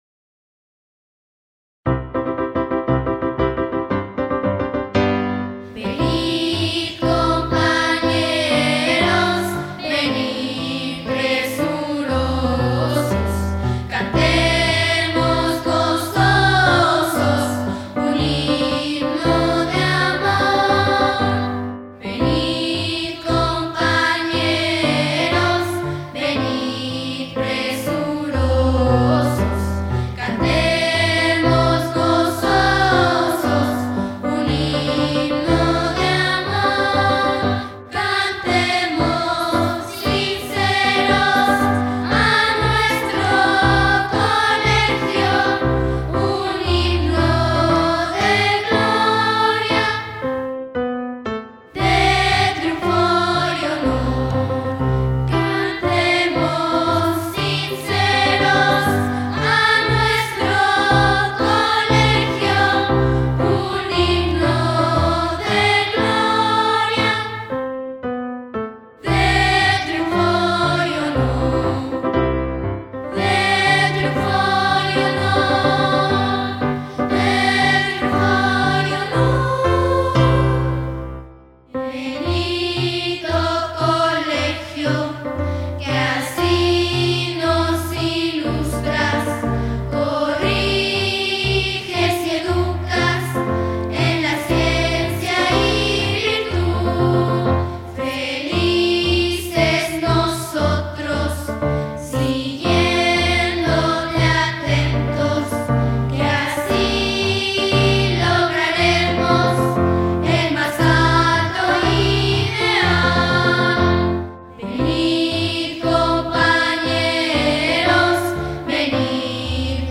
por Voces - Luz del Tepeyac
HIMNO-COLEGIO-LUZ-DEL-TEPEYAC-VOCES.mp3